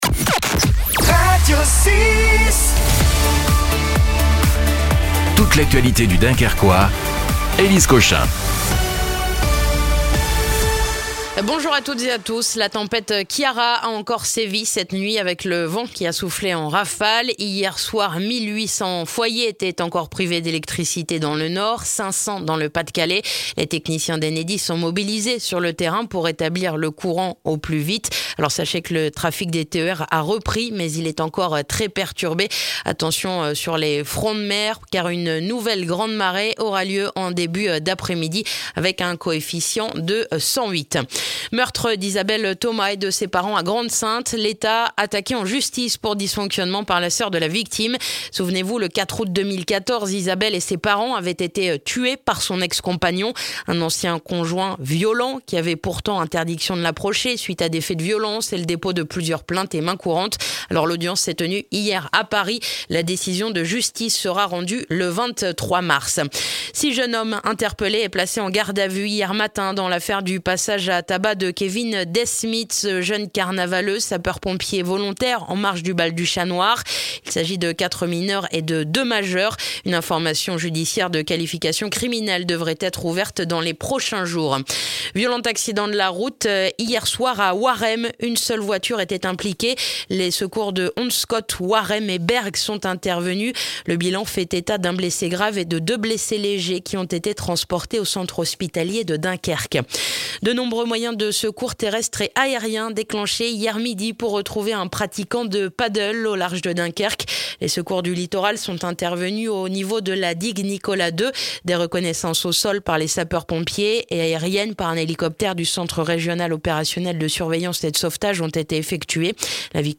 Le journal du mardi 11 février dans le dunkerquois